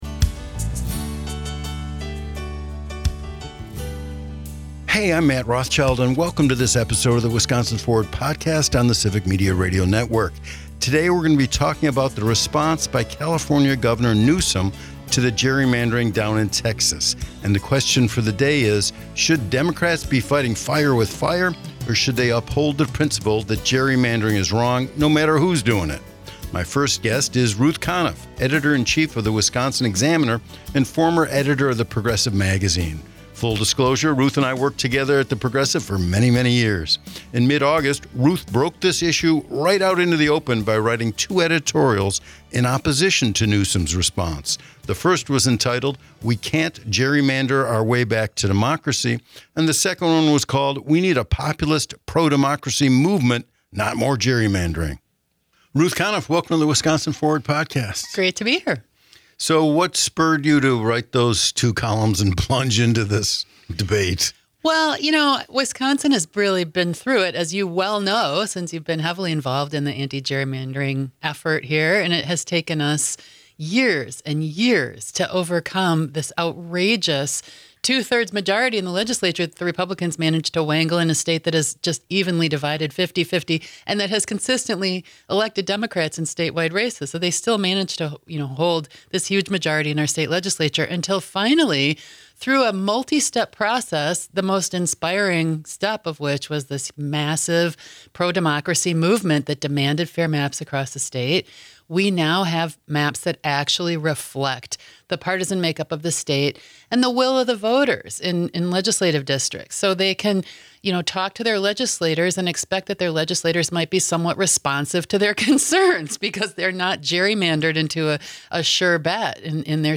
They all don’t agree, and that’s OK. Listen to the conversation, and decide for yourself.